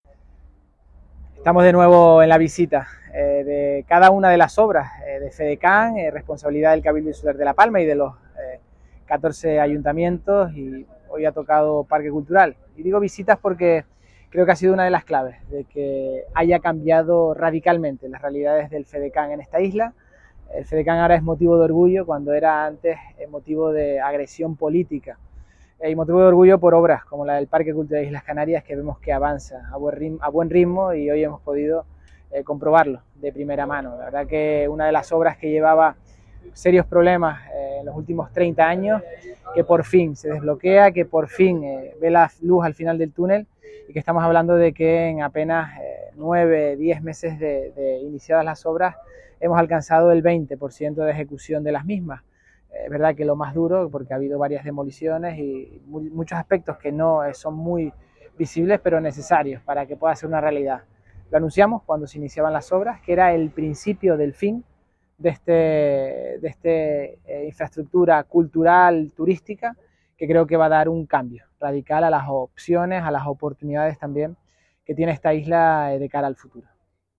Declaraciones audio Mariano Zapata PCIC.mp3